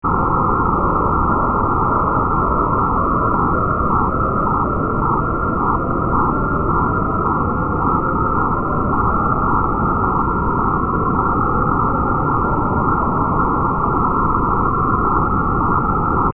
Demo 5: Scales
These major scales span the existence region of dichotic pitch. The scales start at C (65 Hz) and run through 4 octaves to C (1047 Hz).